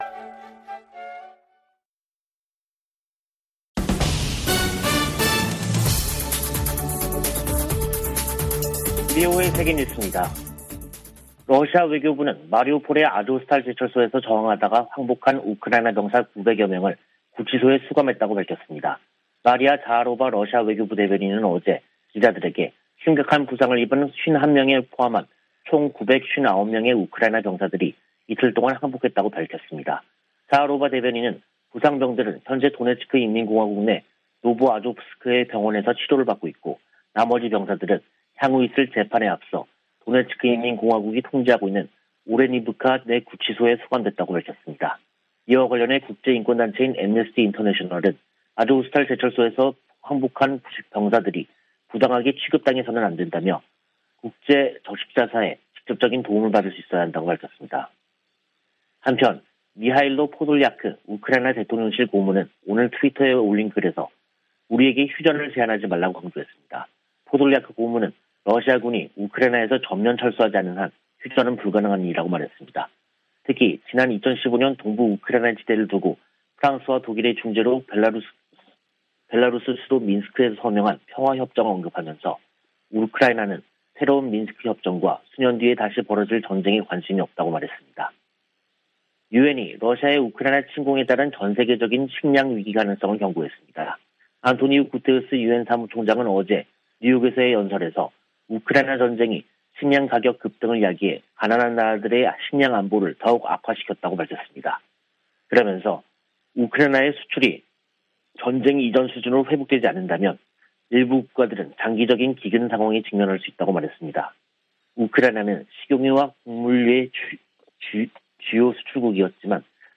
VOA 한국어 간판 뉴스 프로그램 '뉴스 투데이', 2022년 5월 19일 2부 방송입니다.